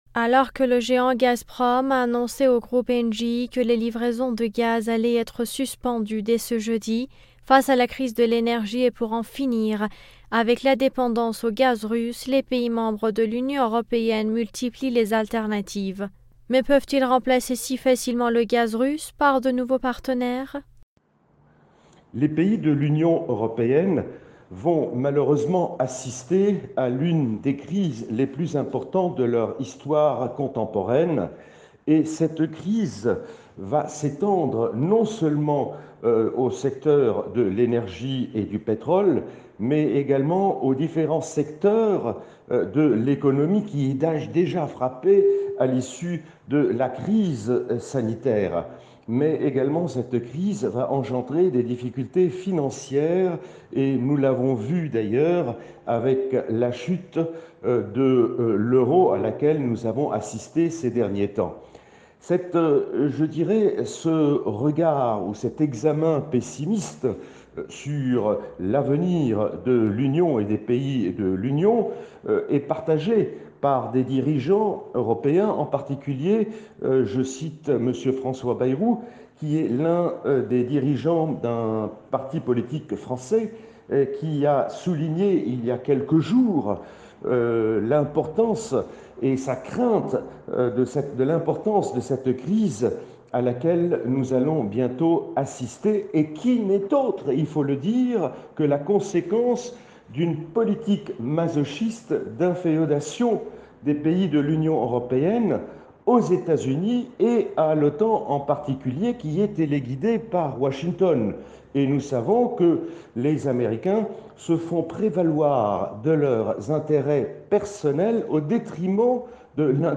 Mots clés Ukraine Europe interview Eléments connexes Pourquoi l’armée américaine est-elle en retard dans la guerre des drones ?